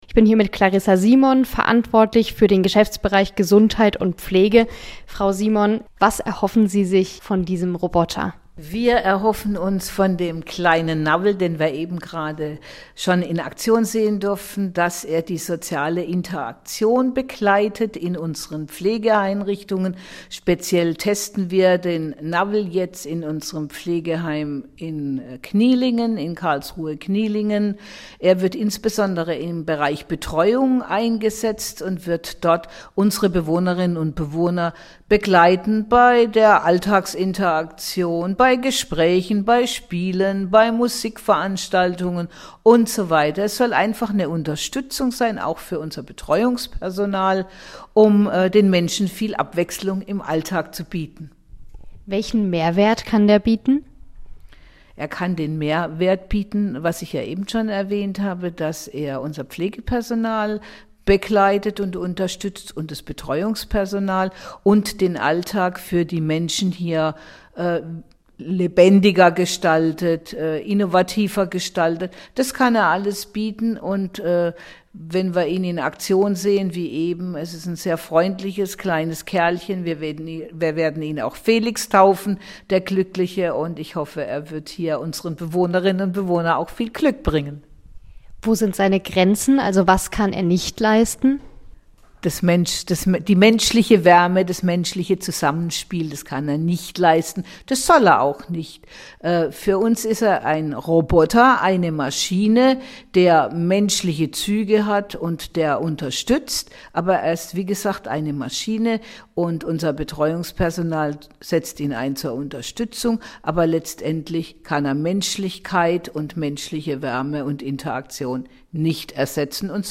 Und auch sonst wirkt "navel" noch etwas unbeholfen: Die vielen Stimmen im Raum verwirren ihn zeitweise, bewegen kann er sich nur wenige Zentimeter.
Mit robotischer Stimme antwortet er: "Hallo, ich bin navel. Wer bist du?"